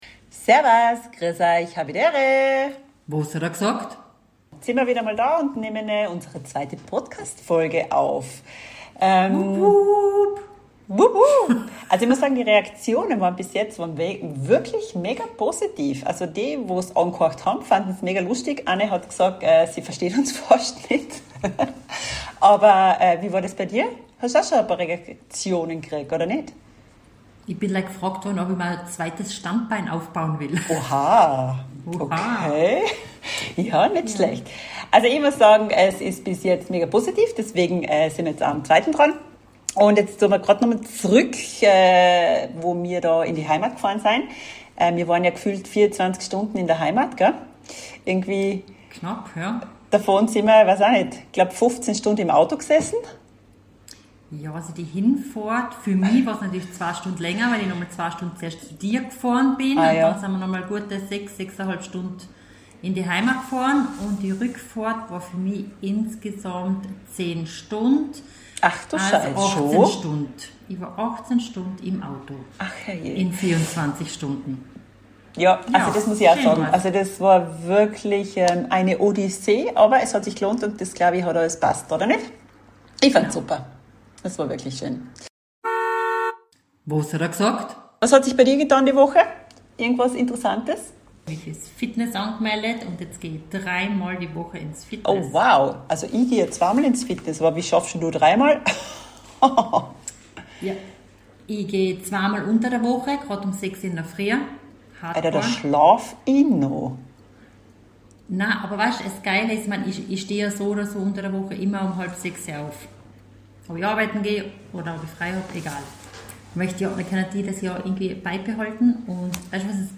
2 Schwesternaus Österreich, seit über 20 Jahren in der Schweiz, minimen Knall, meistens lustig,